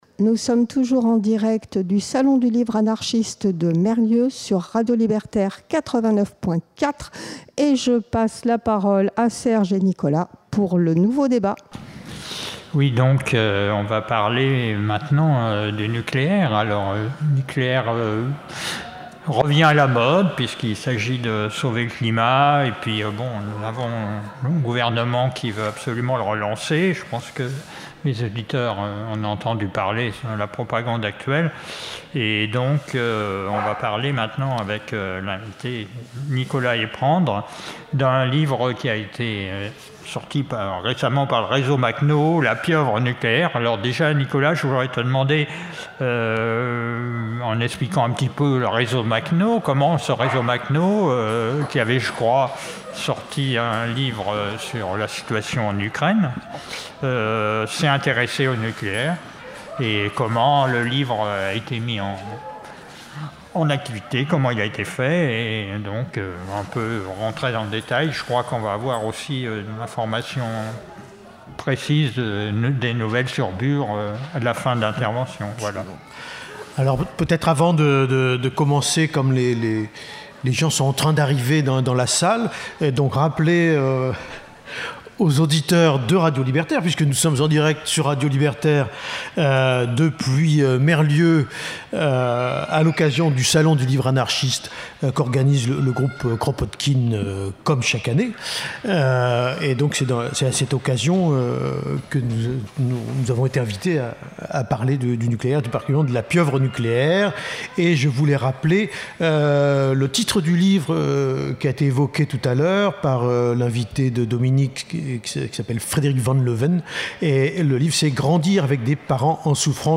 A Merlieux-et-Fouquerolles (02), pendant le salon du livre anarchiste, le 29 septembre 2024, présentation du livre « La Pieuvre Nucléaire » par le réseau Makhno de la Fédération Anarchiste ; suivi d’une prise de paroles sur les actualités de la lutte de Bure.